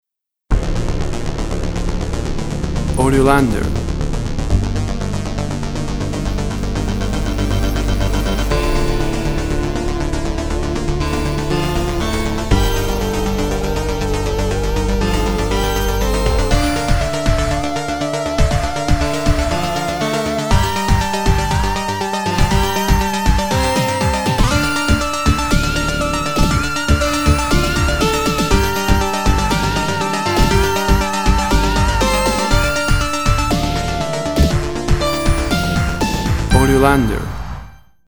Mystery and investigation electronic sounds.
Tempo (BPM) 120